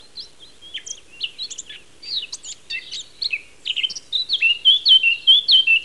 bird.mp3